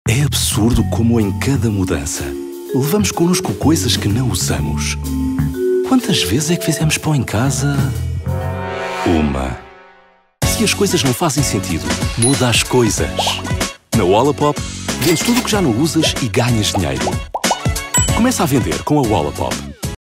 Portuguese
Friendly Warm Calm